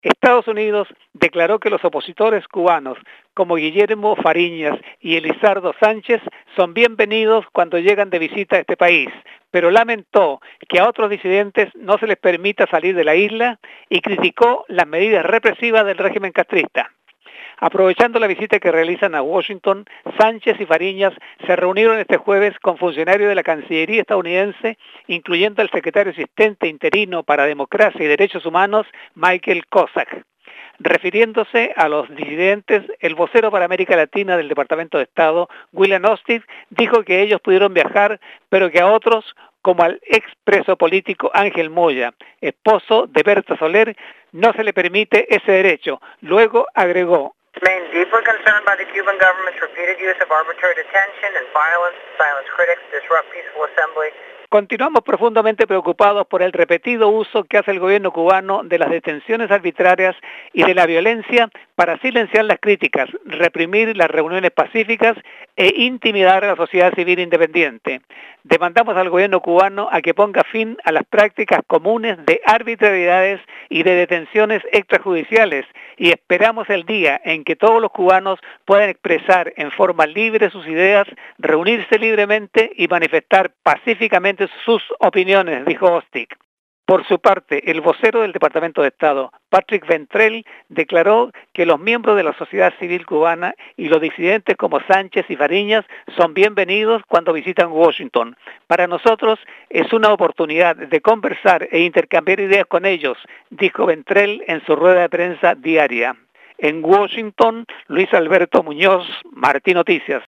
Desde Washington